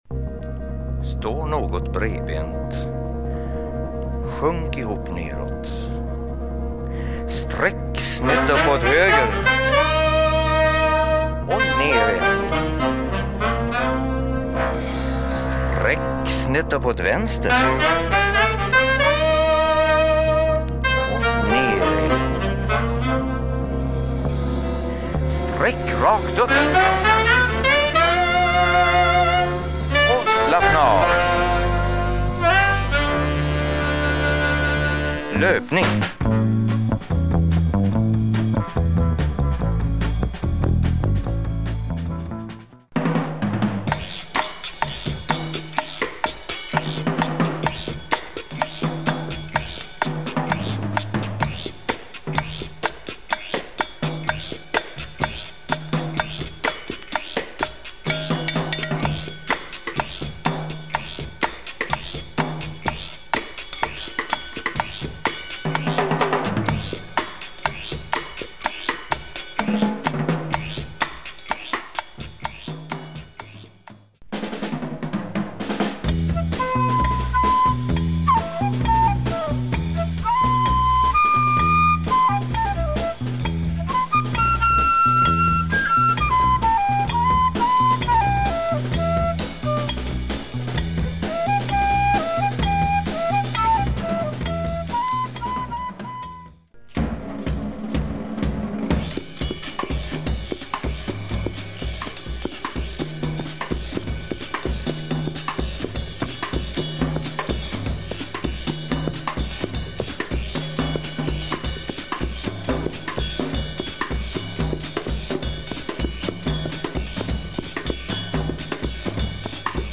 Jazz music for your gym !
Notice the delightful afro latin touch.